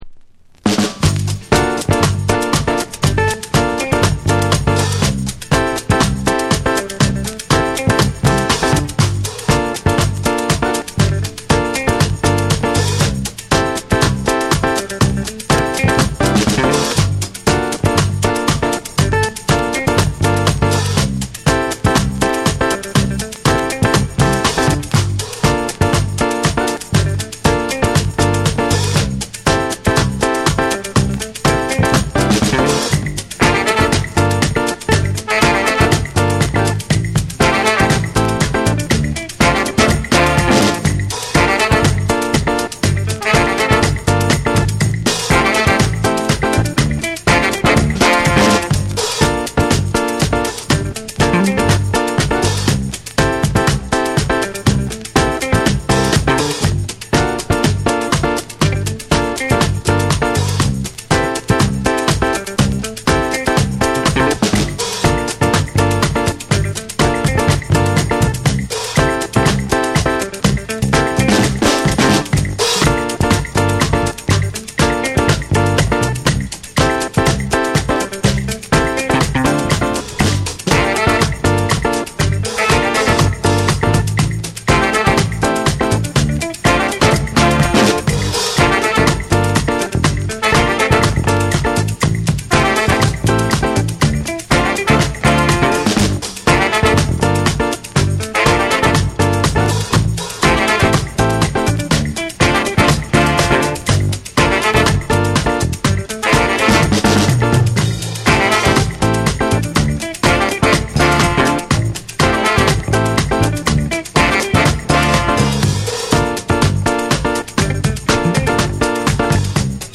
スモーキーでドリーミーなギター＆鍵盤ワーク、ゆったりと揺れるレイドバック・ビートが極上なクロスオーバー・チューン。
BREAKBEATS / ORGANIC GROOVE